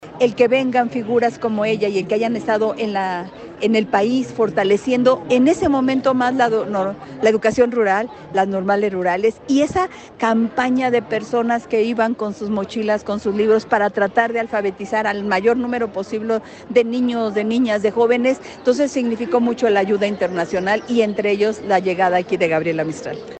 Ramírez brindó esta entrevista a Radio UdeC en el patio de la Escuela Primaria Maestra Gabriela Mistral de Coyoacán en Ciudad de México.
Leticia Ramírez Amaya, secretaria de Educación Pública del gobierno de México.